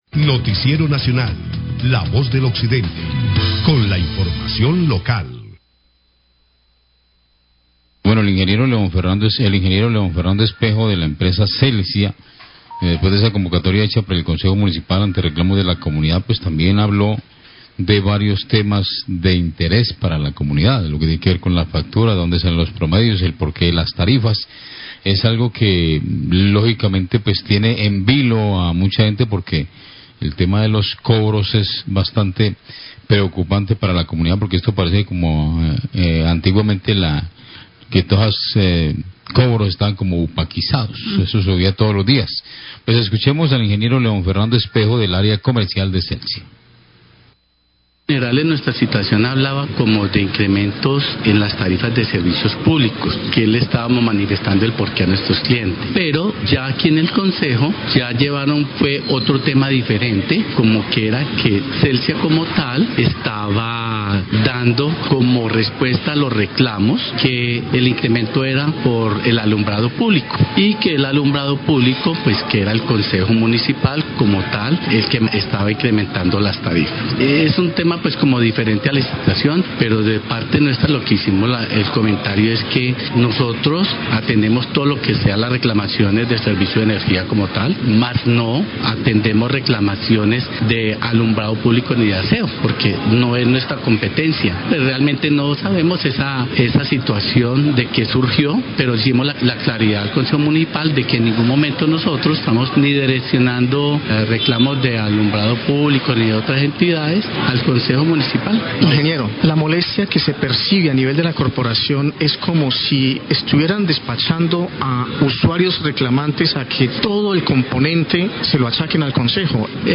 Funcionario Área Comercial Celsia responde a quejas durante debate de control en Concejo de Buga
Radio